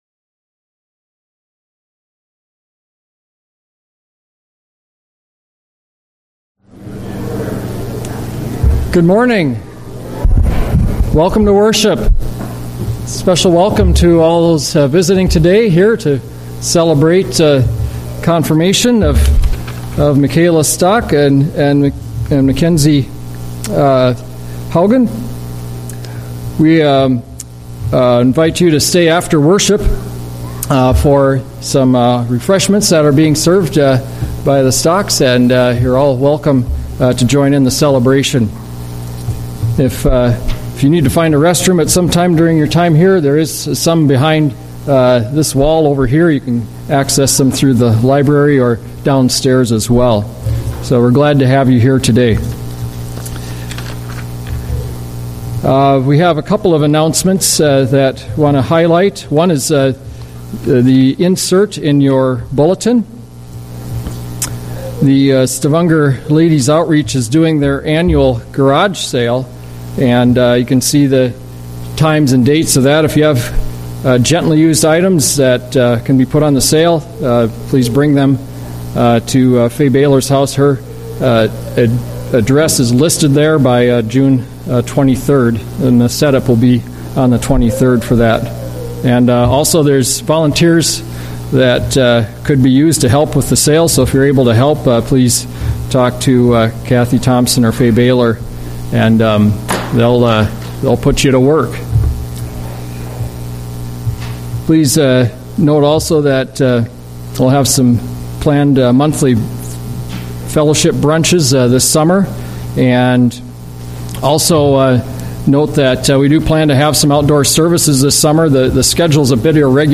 A message from the series "Sunday Worship."